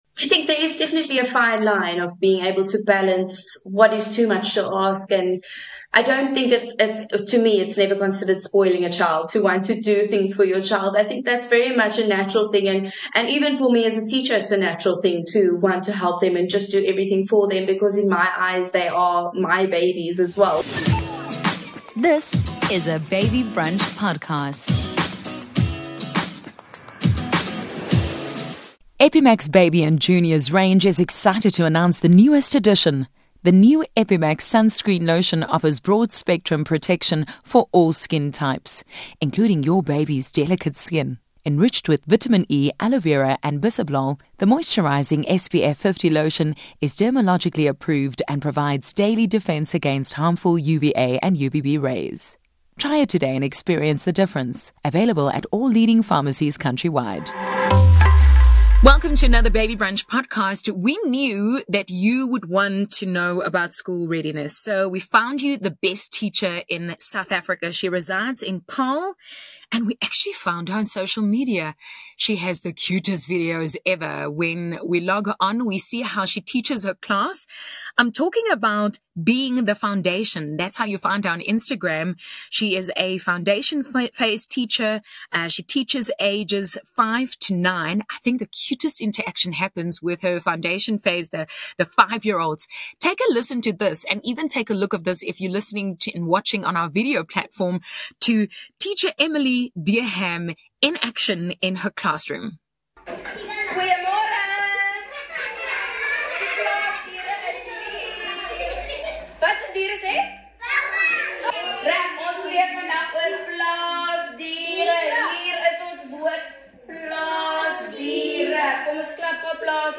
Hosted by Elana Afrika-Bredenkamp, the Baby Brunch | The Parenting Series podcast features fun and insightful conversations with ordinary parents doing extraordinary things. These can serve as a source of support, inspiration and advice for parents, moms and moms-to-be as we delve into the unique experiences of diverse parents.